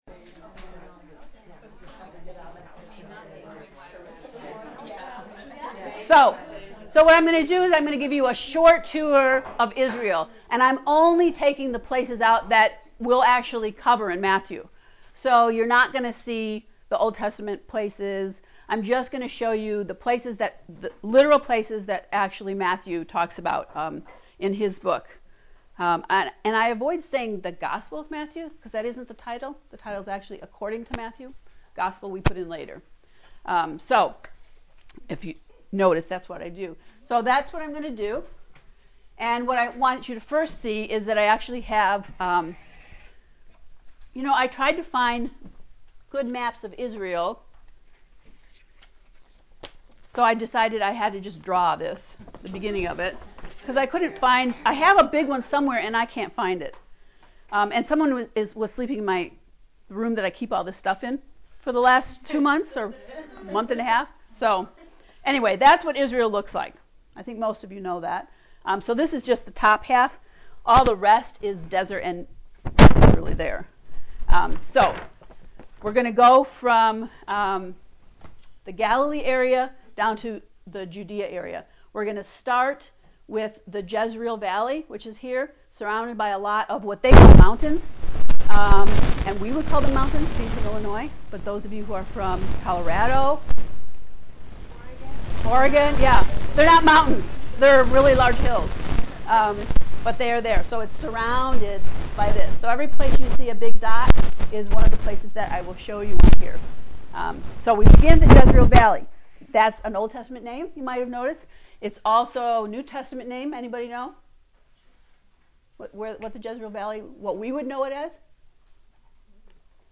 matt-lect-0.mp3